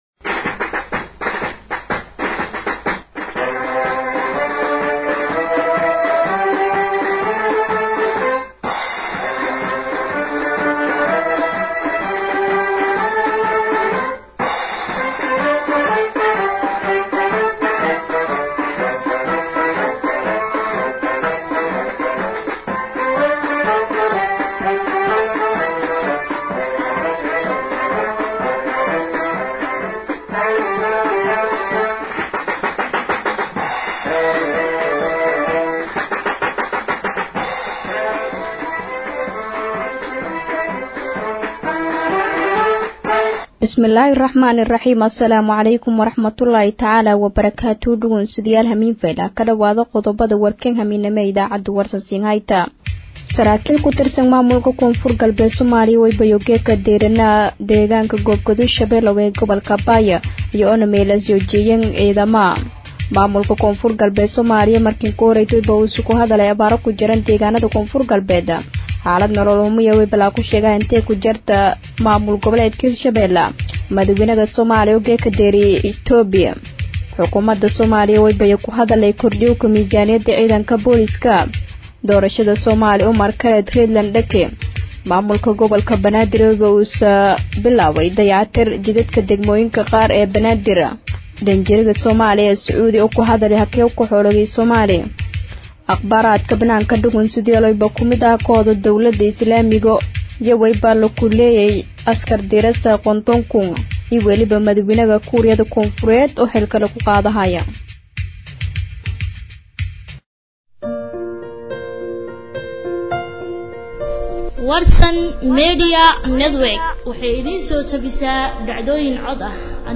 DHAGEYSO:- WARKA HABEEN EE IDAACADA WARSAN CAAWA | warsanradio baydhabo